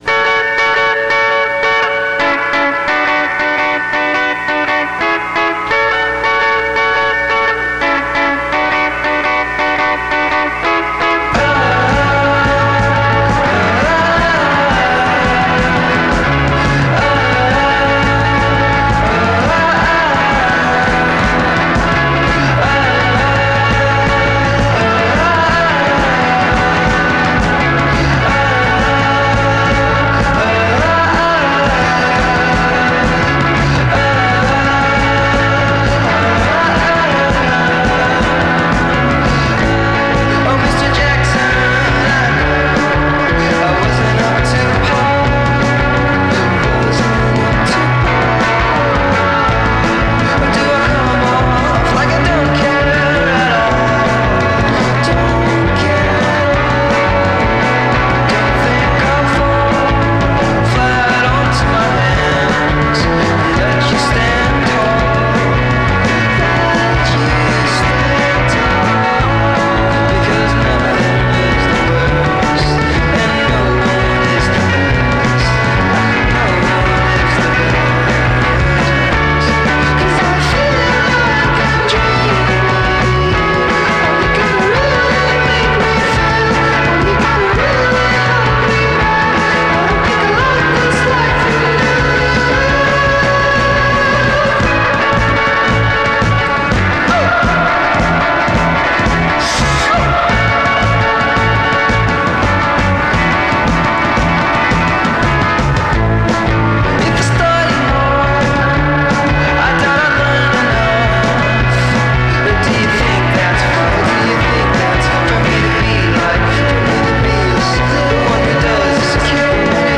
lo-fi summer style